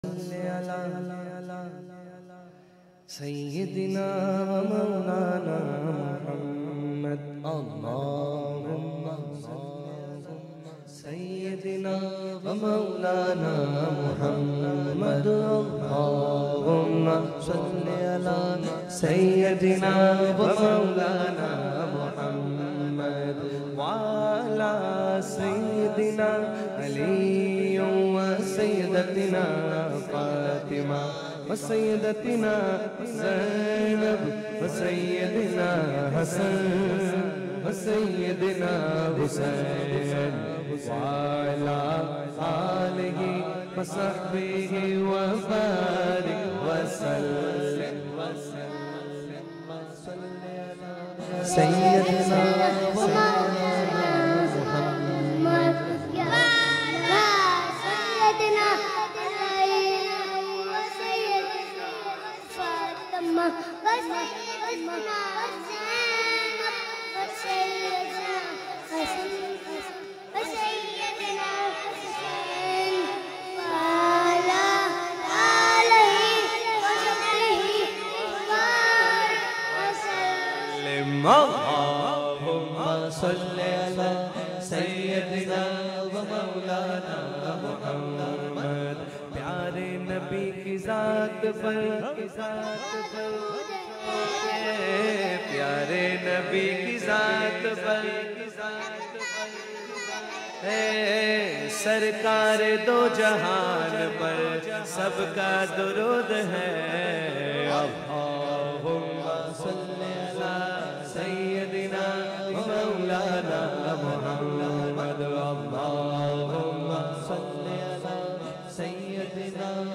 in a Heart-Touching Voice
naat